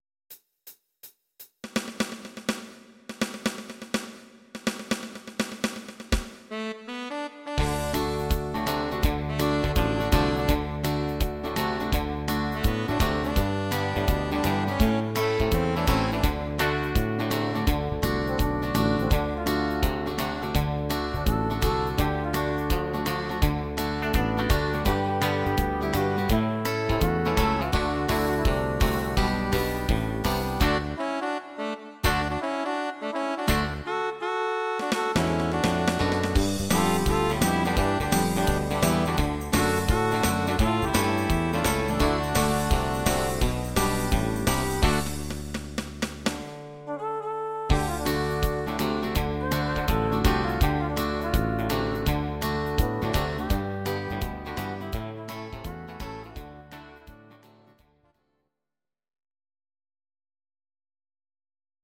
Audio Recordings based on Midi-files
Pop, Country, 2000s